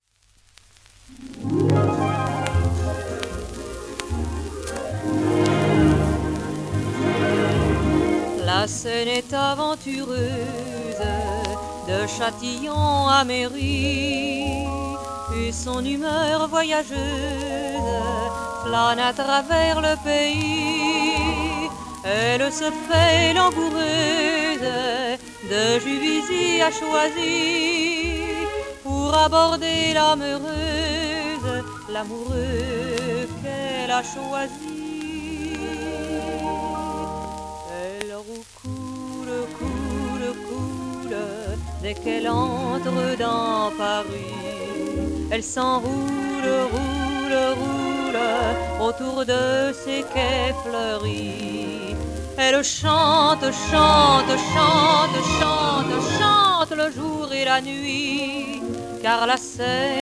w/オーケストラ
戦後のフランスを代表するシャンソン歌手の一人で、100万枚以上のレコードセールスを上げた最初のフランス人女性歌手。